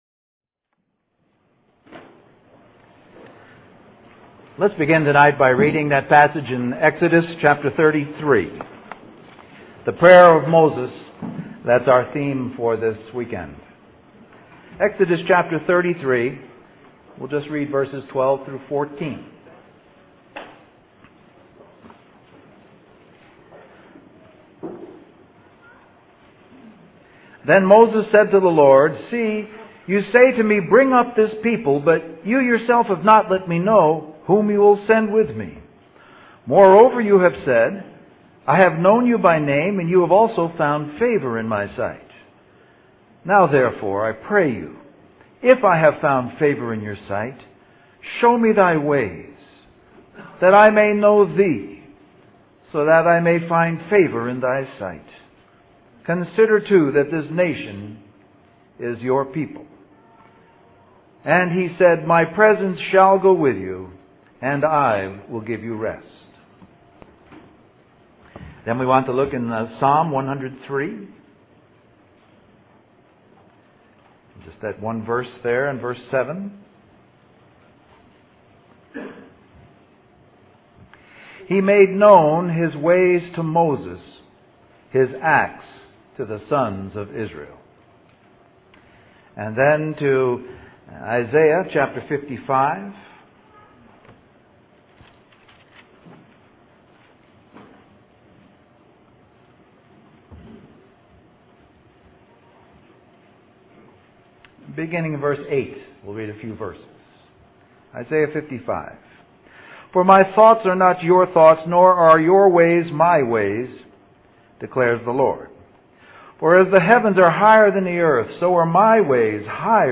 Western Christian Conference